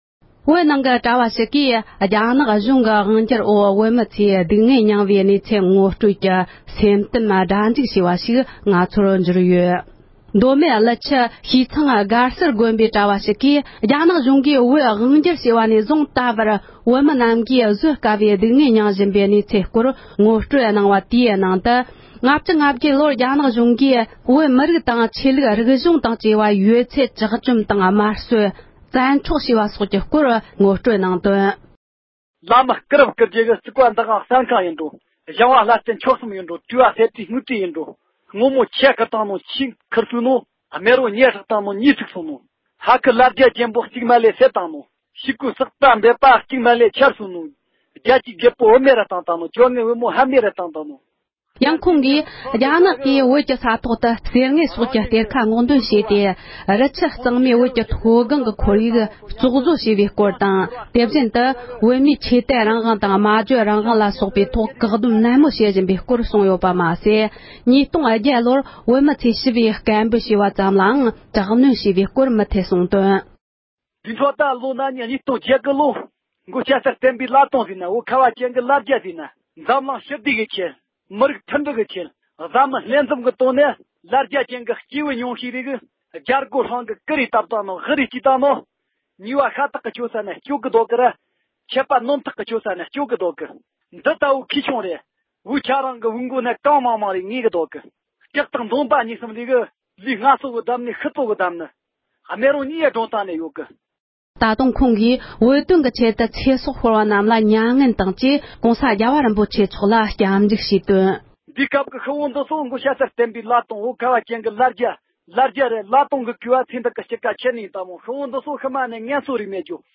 སྒྲ་ལྡན་གསར་འགྱུར། སྒྲ་ཕབ་ལེན།
གོང་དུ་གནས་ཚུལ་ངོ་སྤྲོད་གནང་མཁན་དགེ་འདུན་པ་དེའི་གསུང་ཡོངས་རྫོགས་གཤམ་ལ་གསན་རོགས་ཞུ།